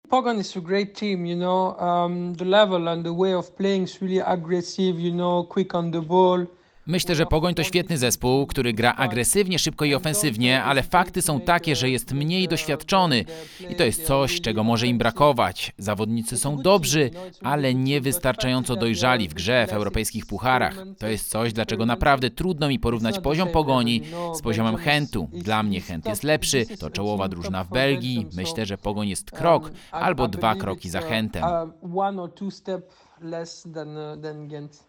NEWS-Belgijski-dziennikarz-o-Gent-Pogon.mp3